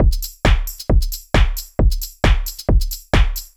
GAR Beat - Mix 1.wav